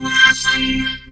VOXVOCODE4.wav